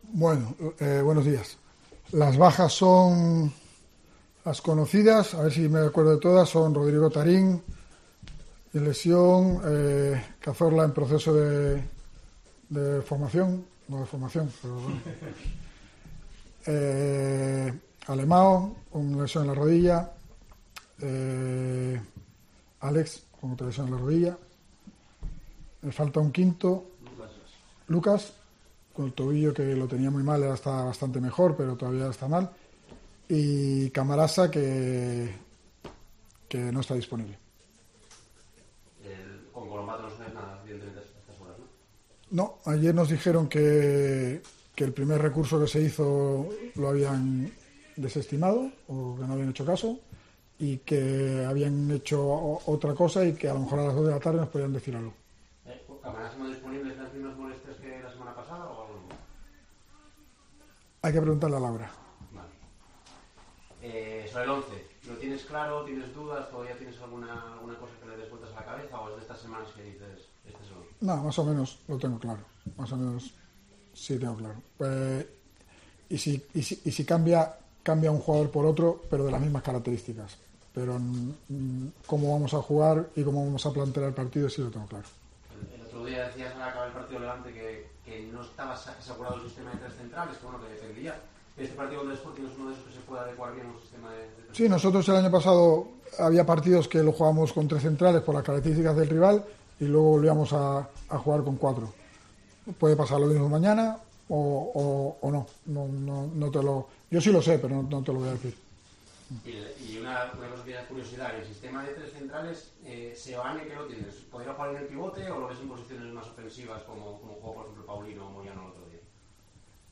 Rueda de prensa de Álvaro Cervera previa al Oviedo-Sporting